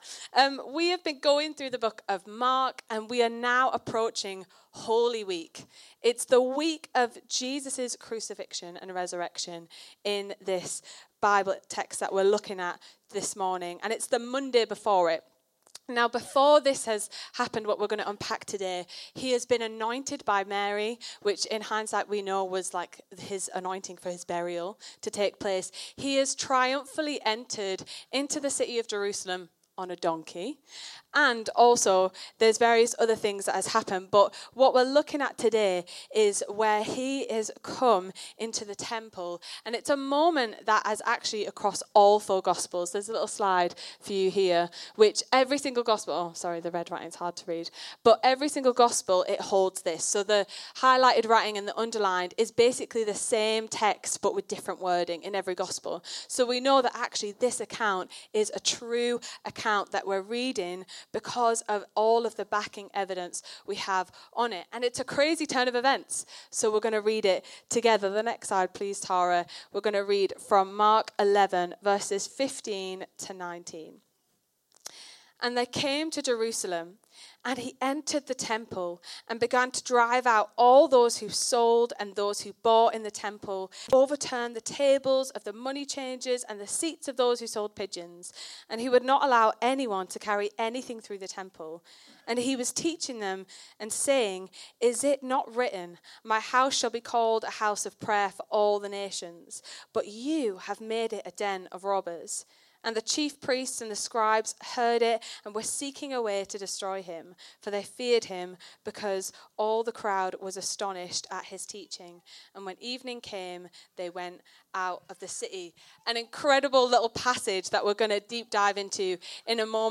Download Jesus and the House of Prayer | Sermons at Trinity Church